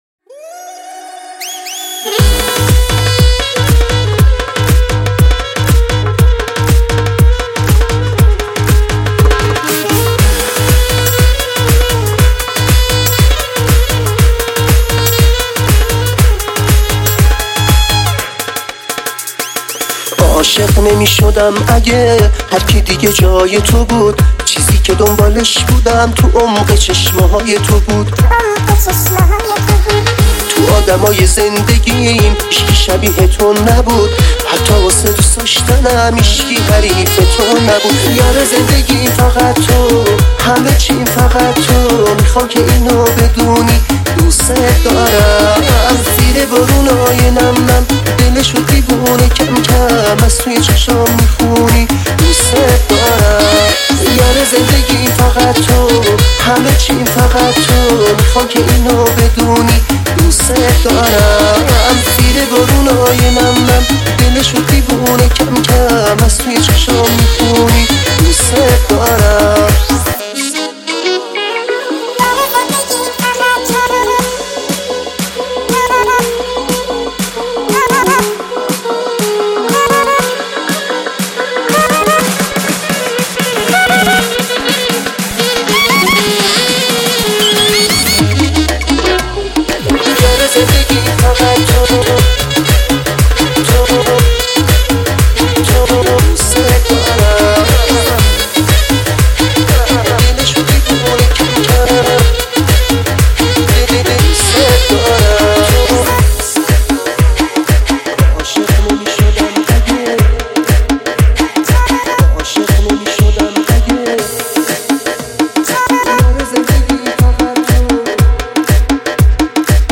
موسیقی